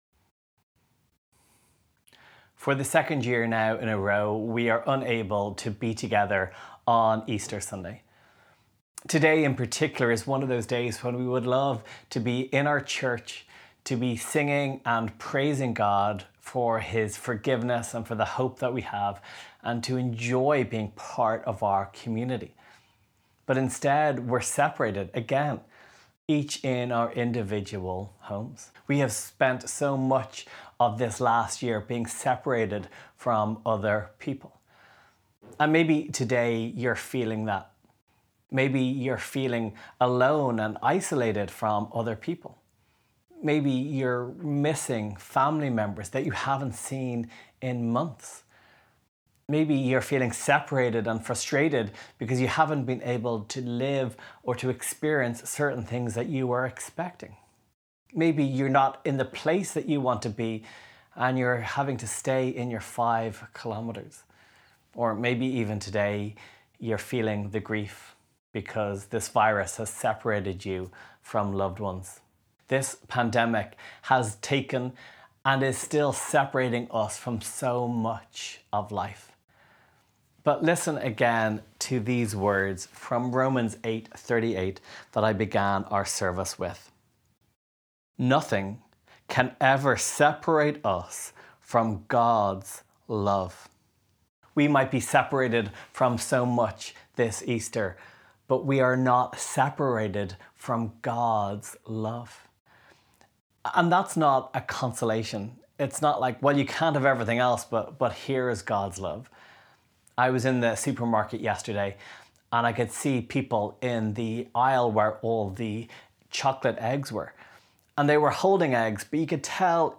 Downloads Download Easter Sunday Sermon MP3 March/Easter Reading Plan Share this: Share on X (Opens in new window) X Share on Facebook (Opens in new window) Facebook Like Loading...